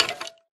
mob / skeletonhurt2